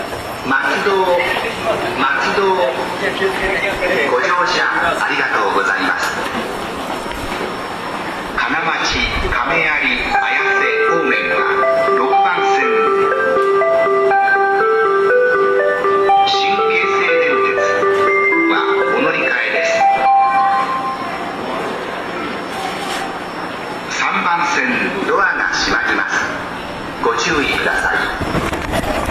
せせらぎ 変更したのにUNIPEXのまま…